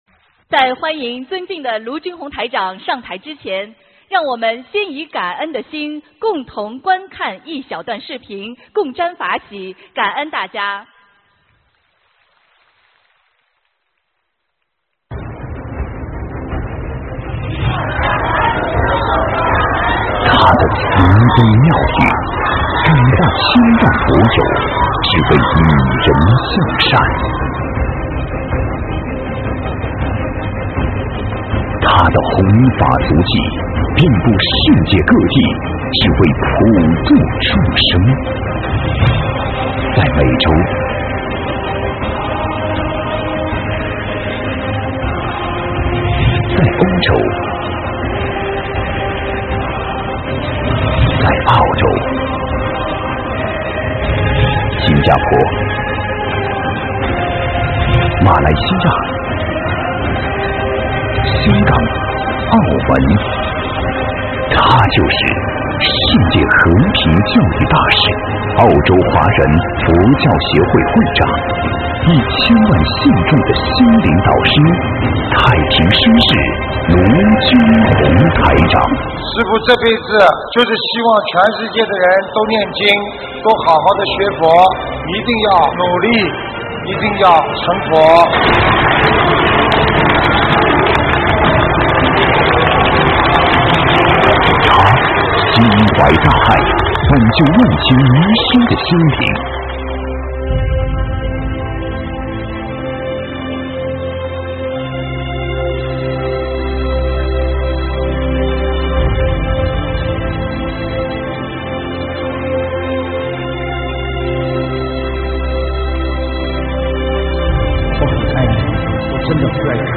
【2017马来西亚·槟城】4月15日 大法会 文字+音频 - 2017法会合集 (全) 慈悲妙音